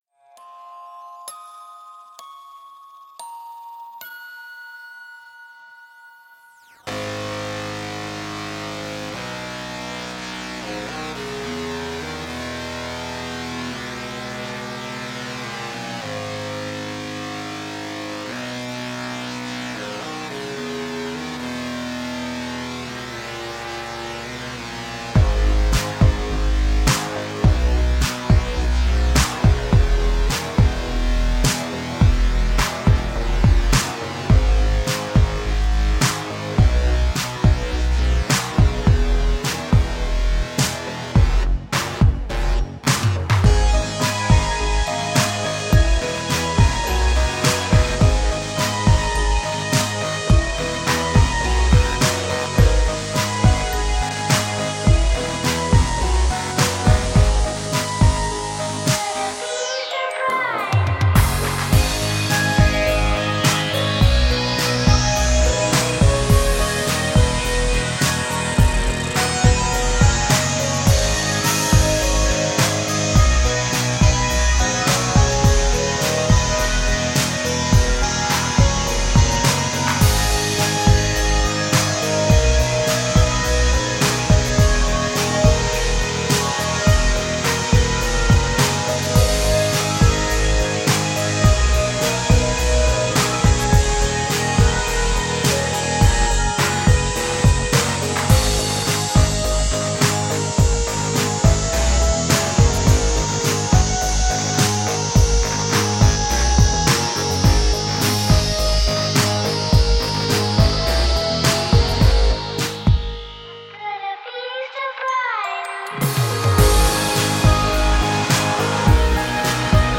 Welcome to Folktronica!
Tagged as: Electronica, Folk-Rock